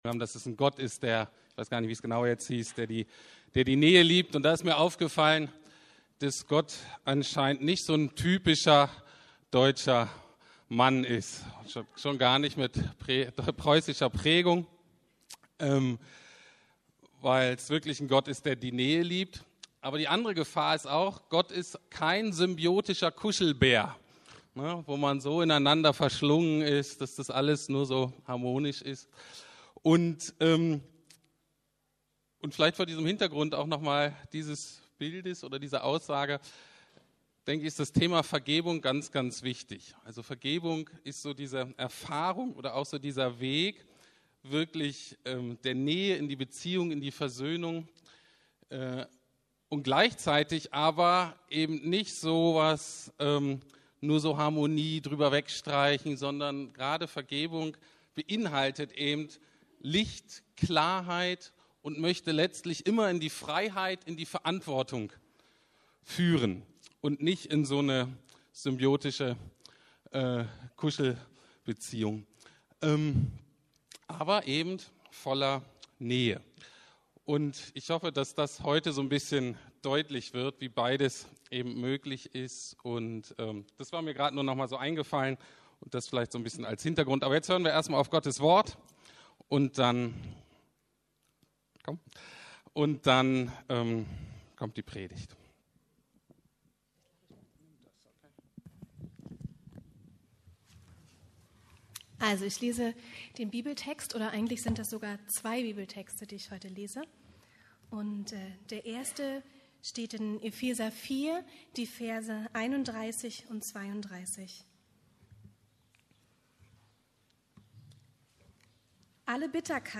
Vergebung – wie mache ich das? ~ Predigten der LUKAS GEMEINDE Podcast